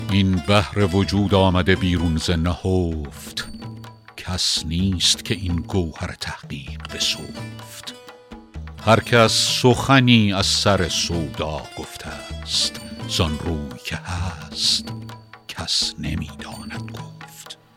رباعی ۸ به خوانش